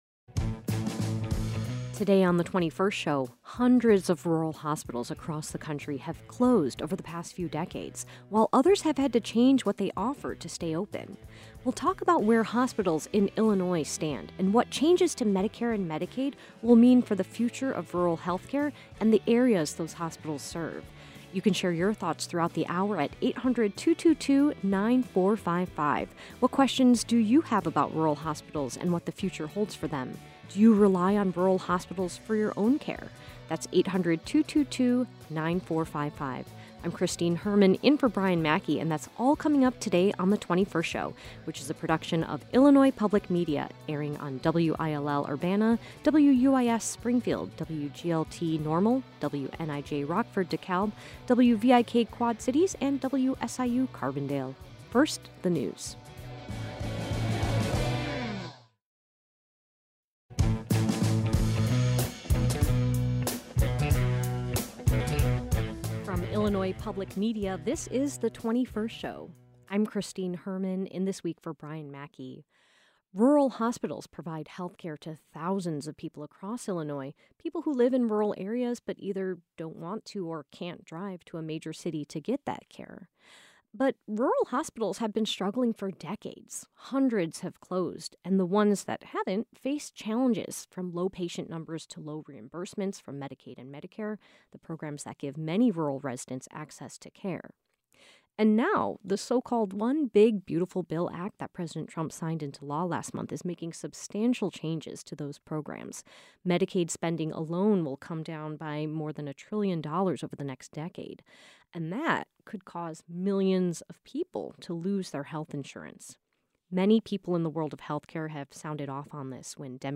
Experts in rural health care discuss the state of rural hospitals across Illinois and how many are preparing for the challenges ahead.